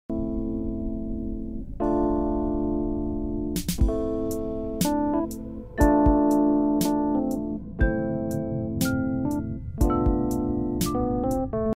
📚 Perfect sound for focusing, sound effects free download